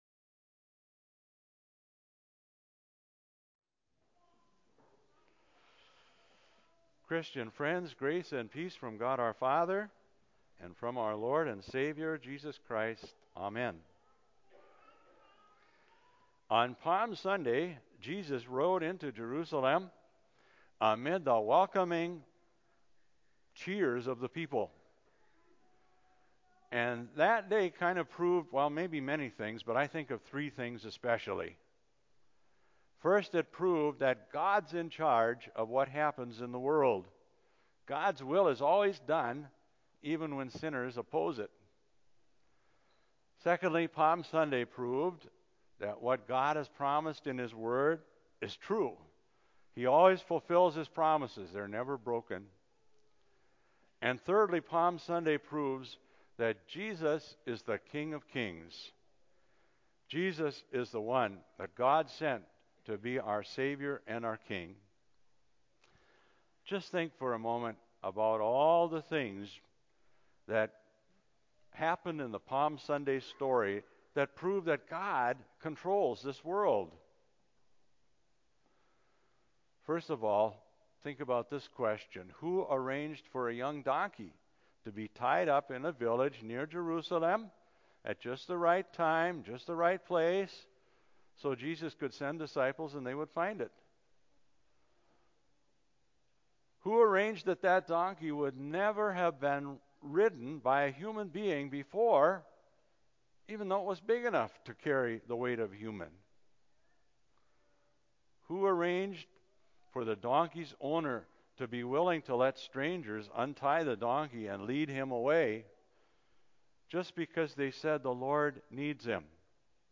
Sermon on the Mount – Ask, Seek, Knock – Mount Hope Lutheran Church